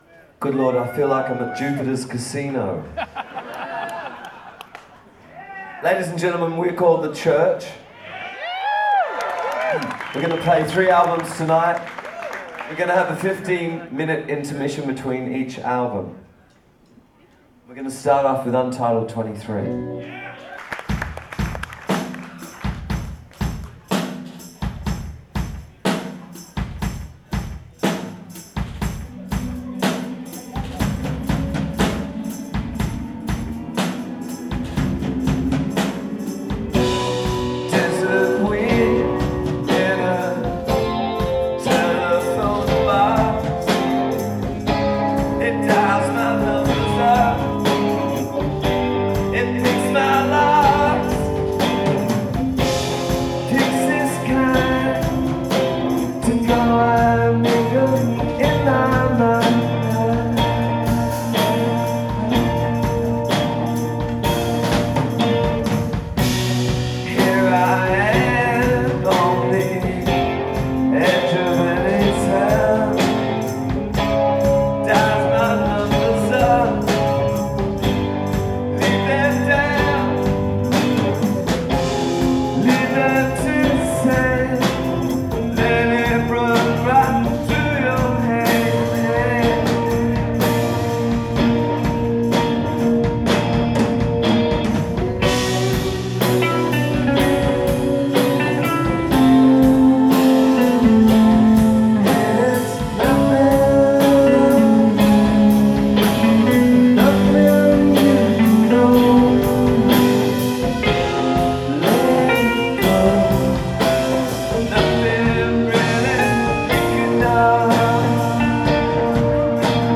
Live at Showcase Live
in Foxboro, MA